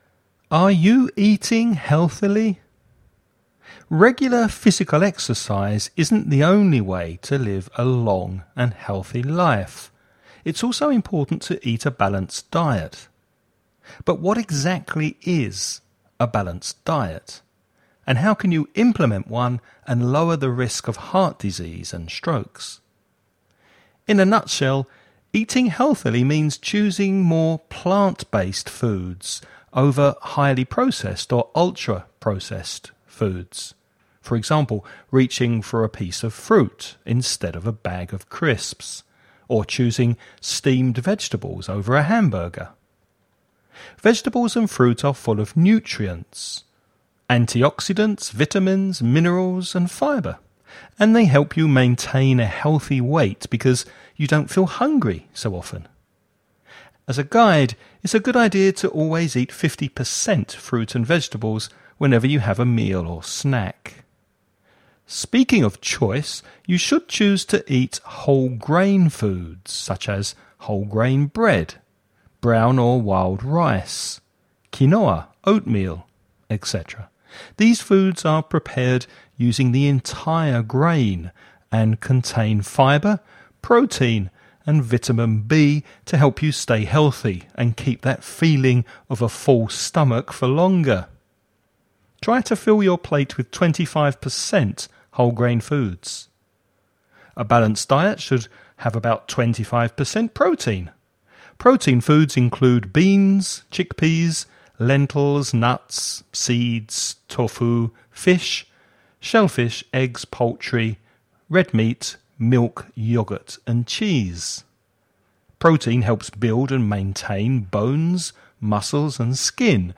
Listening Practice
Before you listen about a man talking about a healthy diet, read the following questions.